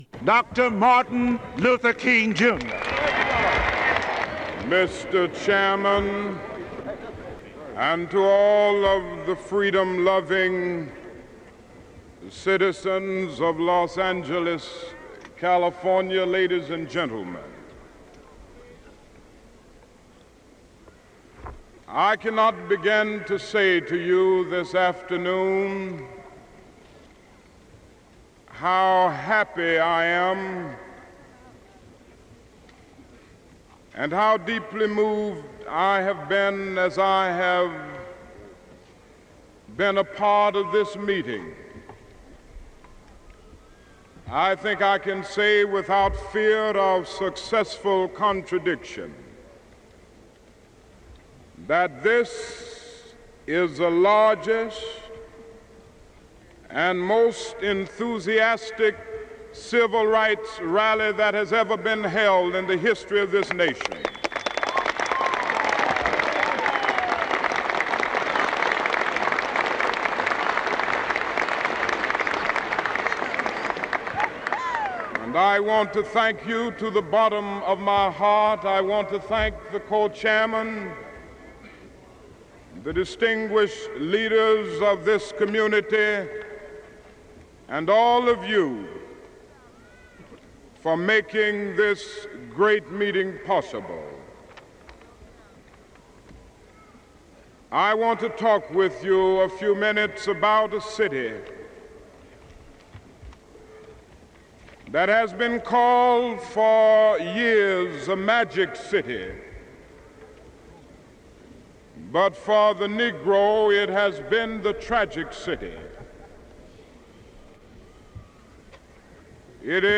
We Want to be Free (Freedom Rally, May 26, 1963)